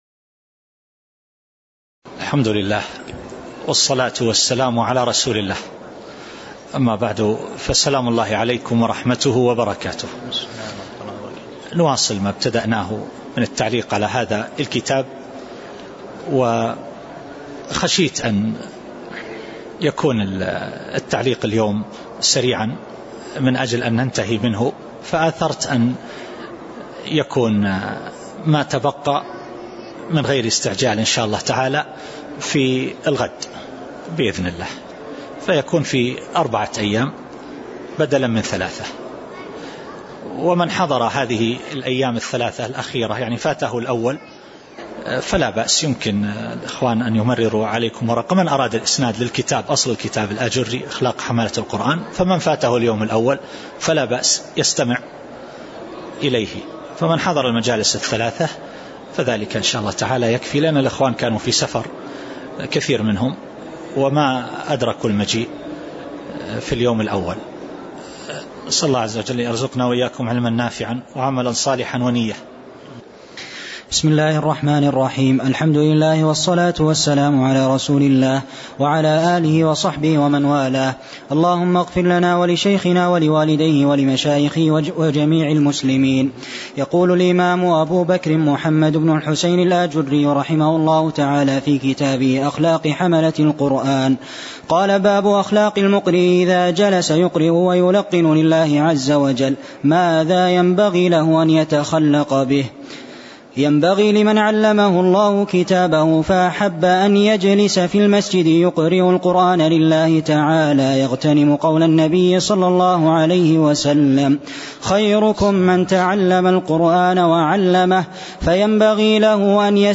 تاريخ النشر ١٢ صفر ١٤٣٨ هـ المكان: المسجد النبوي الشيخ